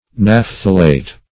Search Result for " naphthalate" : The Collaborative International Dictionary of English v.0.48: Naphthalate \Naph"tha*late\, n. (Chem.) A salt of naphthalic acid; a phthalate.